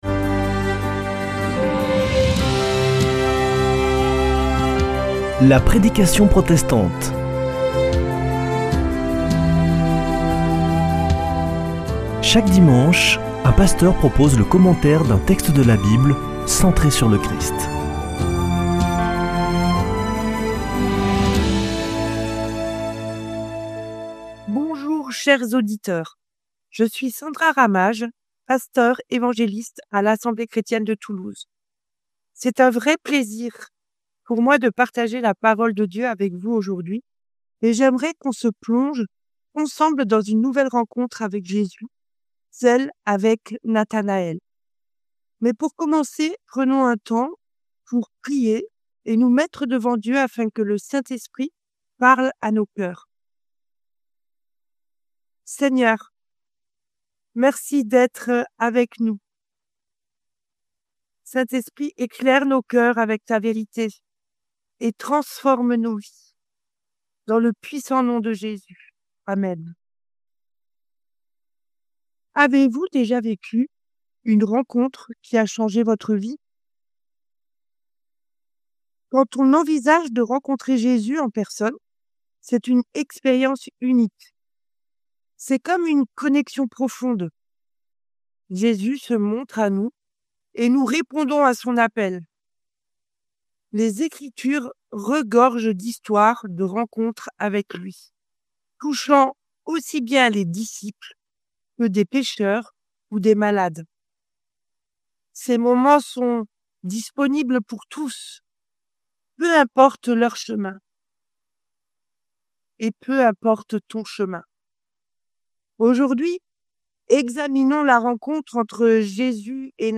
La prédication protestante du 09 mars